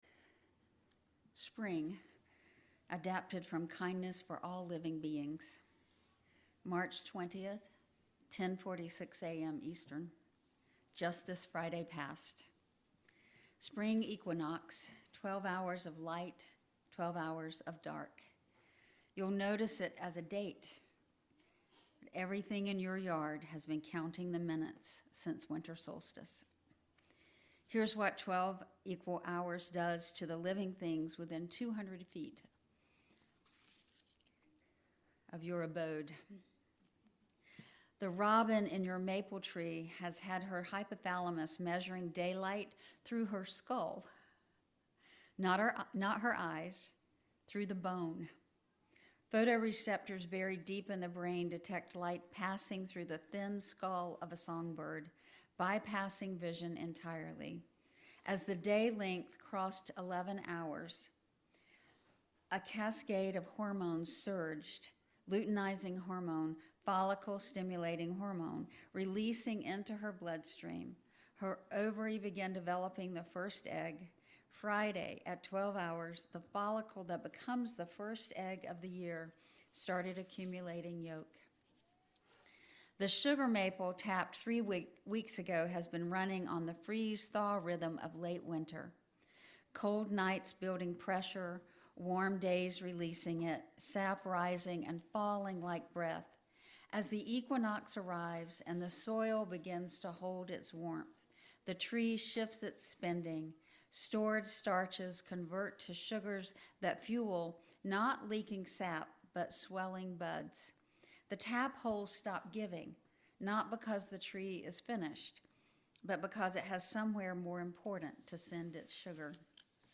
This sermon explores the biological and spiritual transitions of spring to advocate for a societal shift from extractive economies to gift economies.